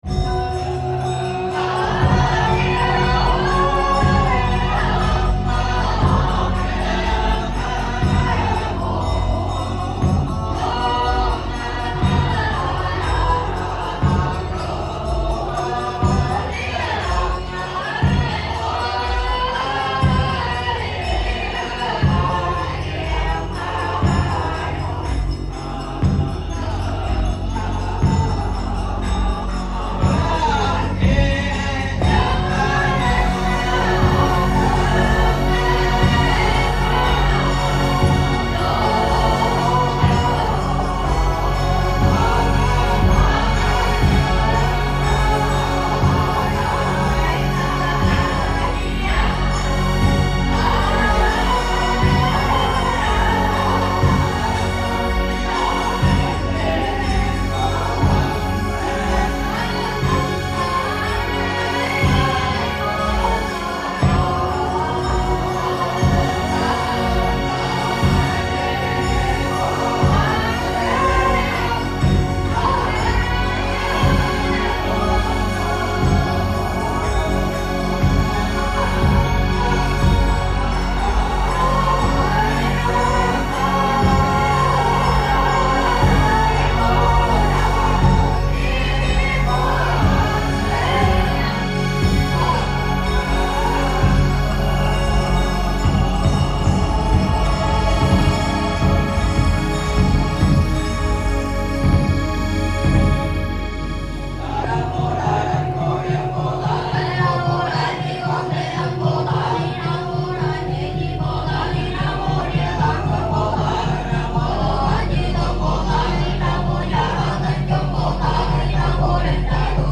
Chanting in Hue reimagined